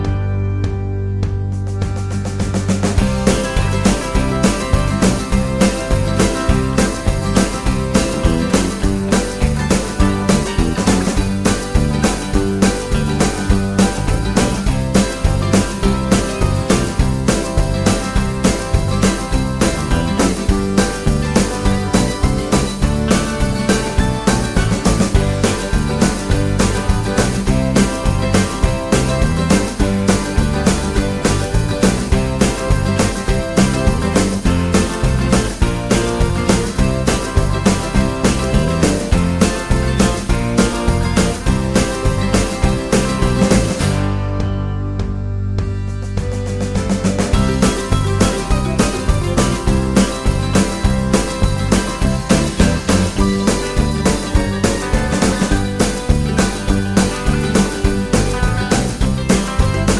Minus Sax Irish 4:47 Buy £1.50